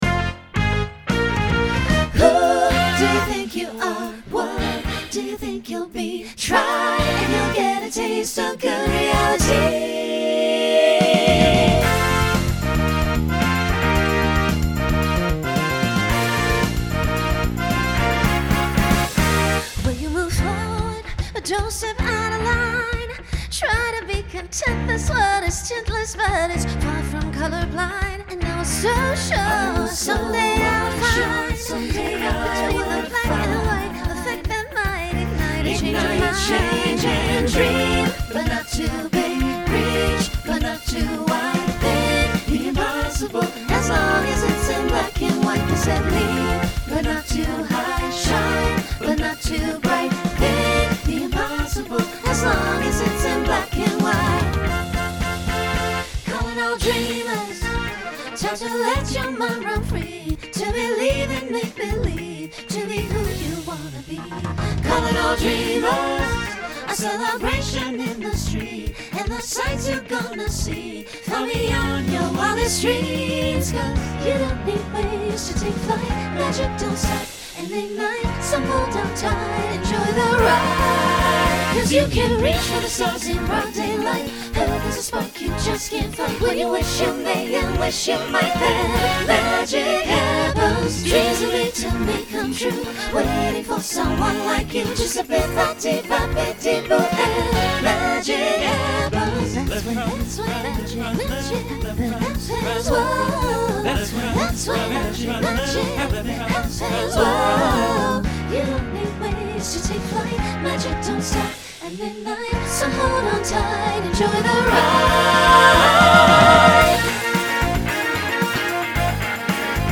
New SSA voicing for 2026.
Genre Pop/Dance Instrumental combo
Voicing SATB , SSA